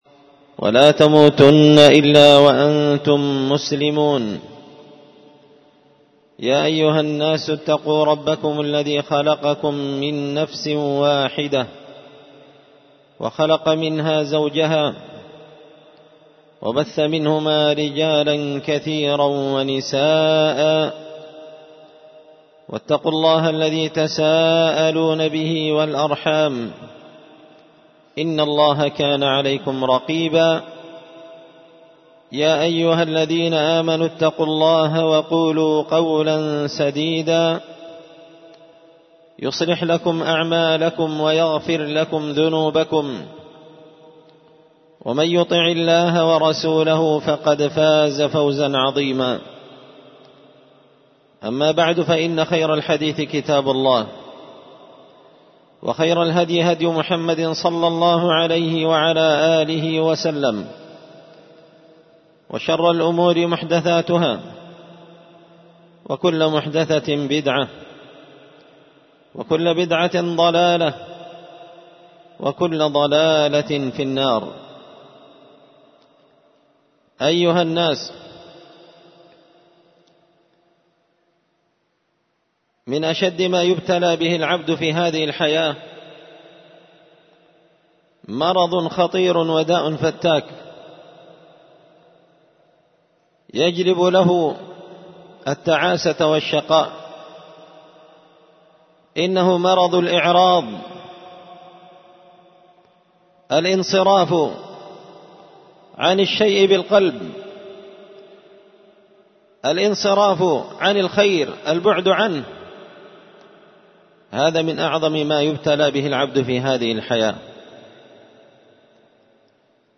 خطبة جمعة بعنوان التبيان لخطر الإعراض عن القرآن الجمعة 15 جماد أول 1444هـ